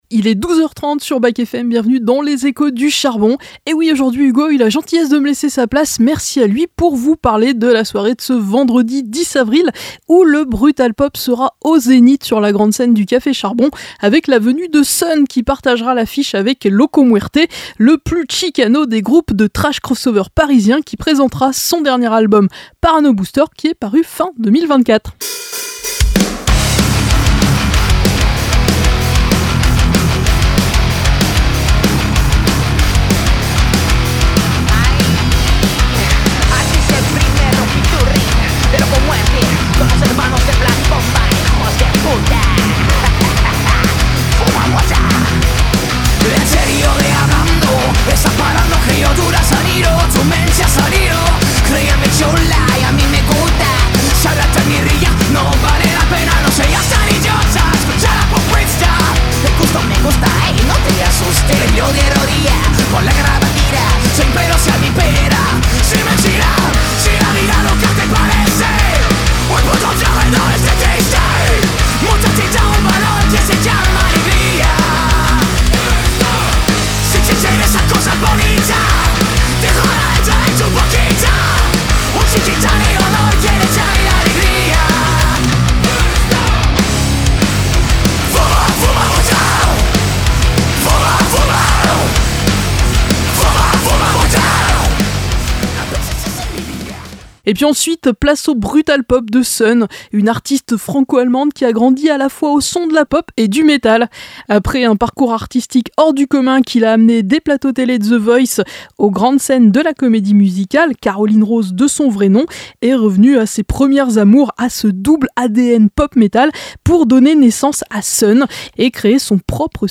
Les Échos du Café Charbon - Interview